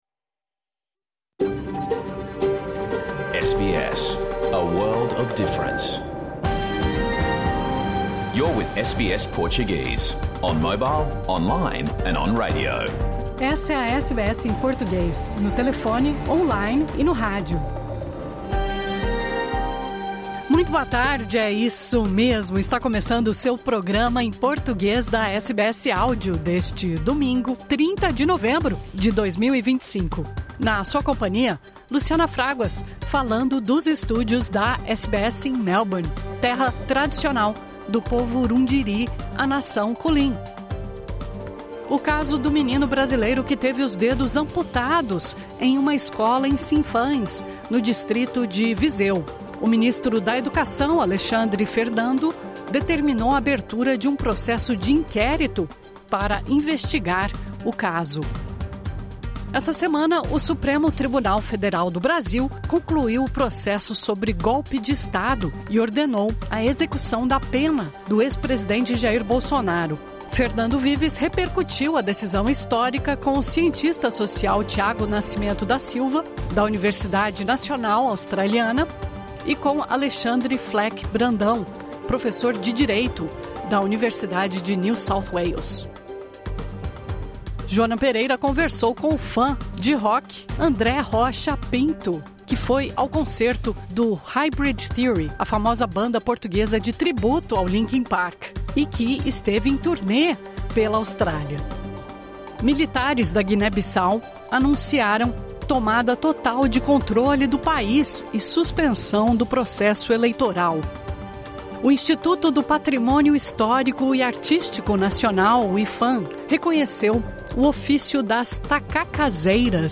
Programa ao vivo | Domingo 30 de novembro